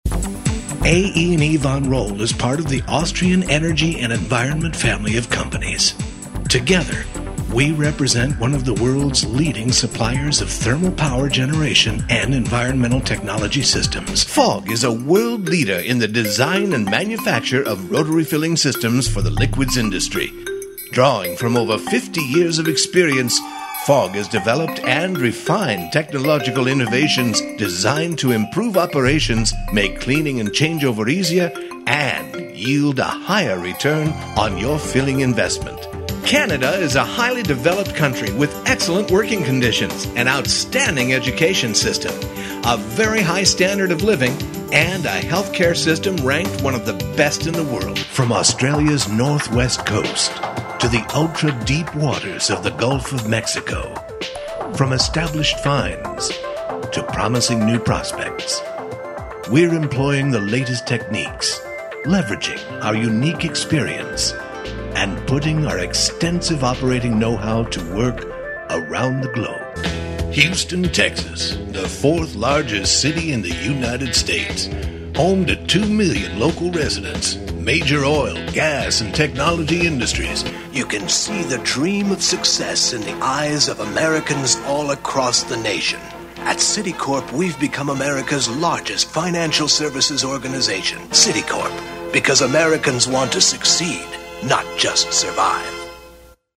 A VOICE THAT\'S COMPLETELY DIFFERENT - WARM, GENUINE, FRIENDLY. APPROACHABLE, CONVERSATIONAL, YET AUTHORITATIVE, AND BELIEVABLE - AND A MASTER OF MANY DIALECTS AND ACCENTS, AS WELL.
Sprechprobe: Industrie (Muttersprache):